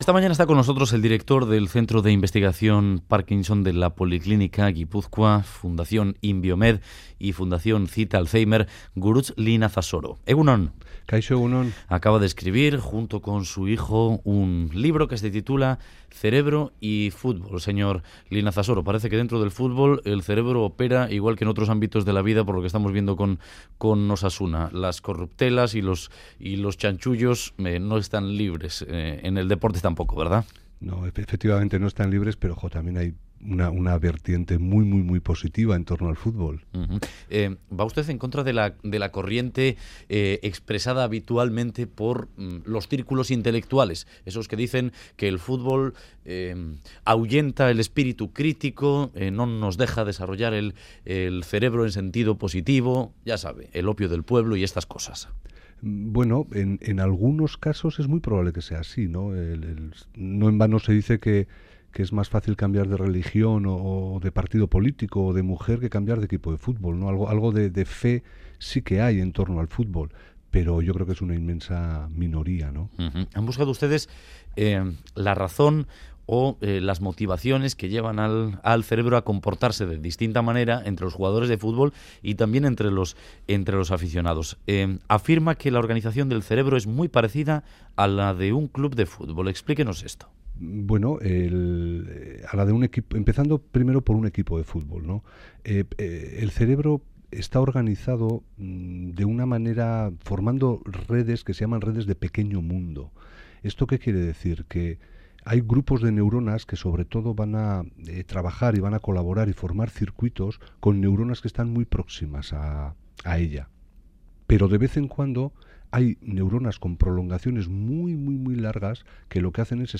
BOULEVARD